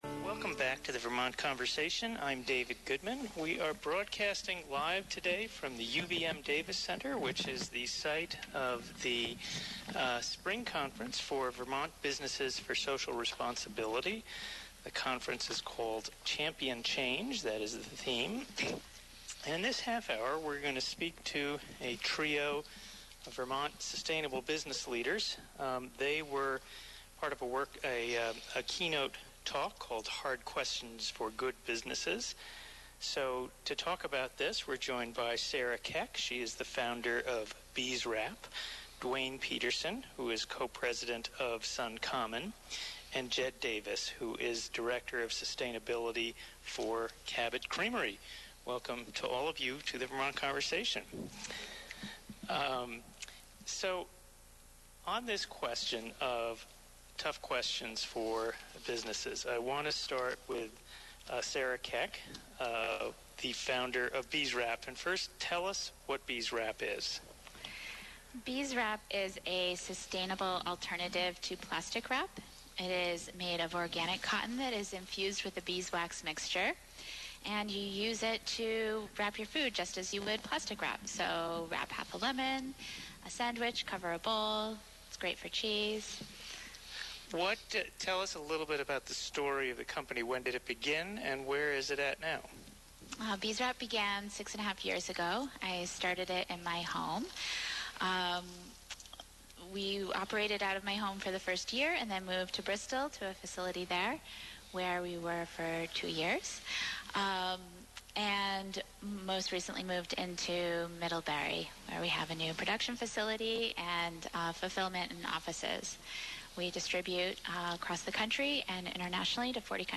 How can business be a force for positive change? Three of Vermont’s sustainable business leaders offer their thoughts. (May 15, 2019 broadcast)